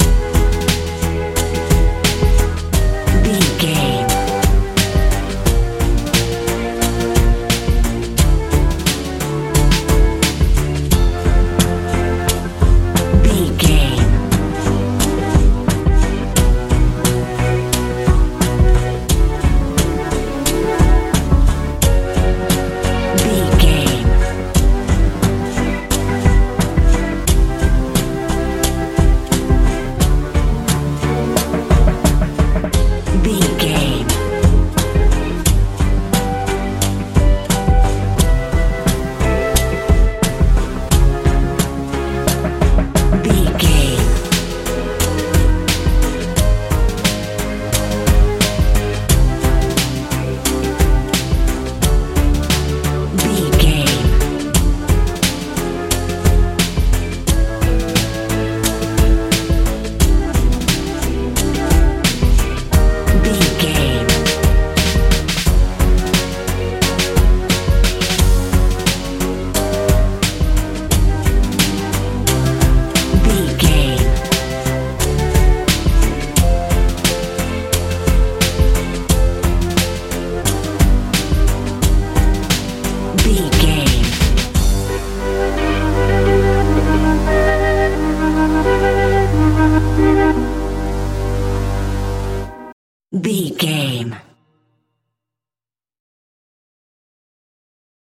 hip hop feel
Aeolian/Minor
light
mellow
synthesiser
bass guitar
drums
80s
90s
strange
anxious